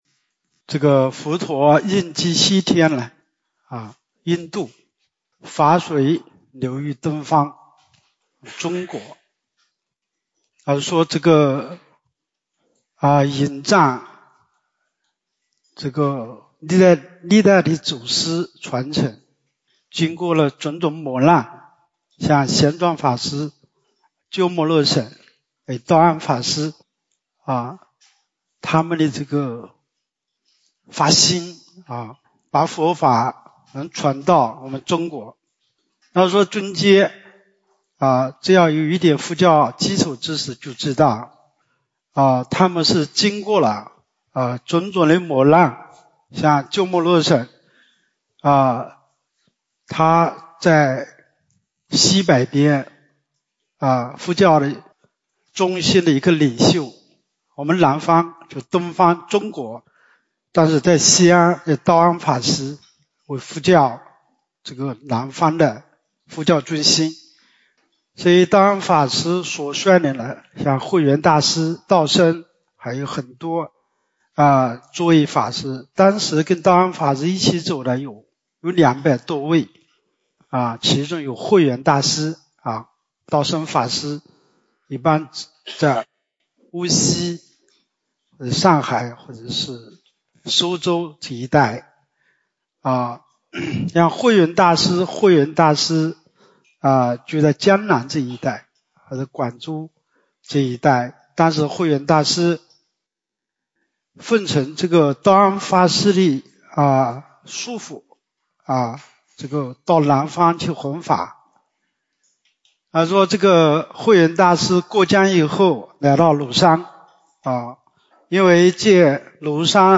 果公大和尚圆寂32周年纪念日开示